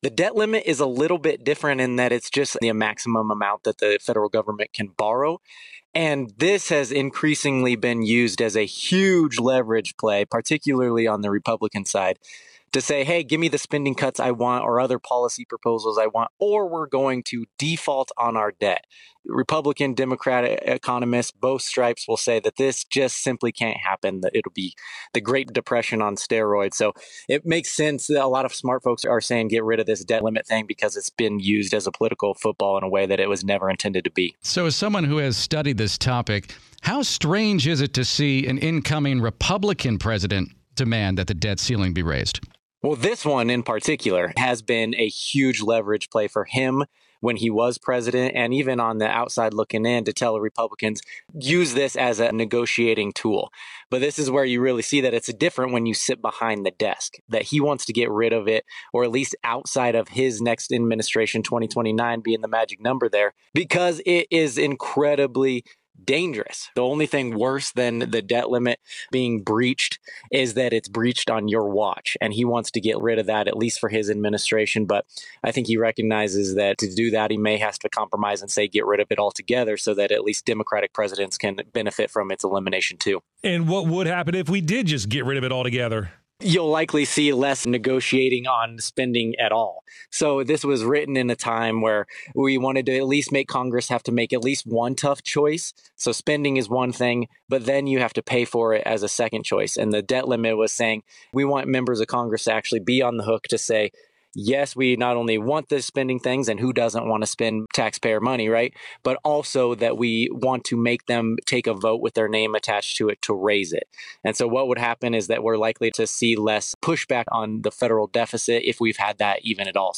Listen to the full interview below or read the transcript, which has been lightly edited for clarity.